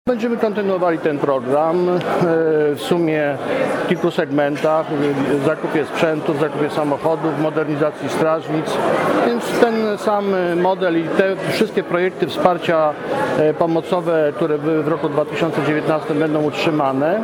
Wypowiedź Adama Struzika: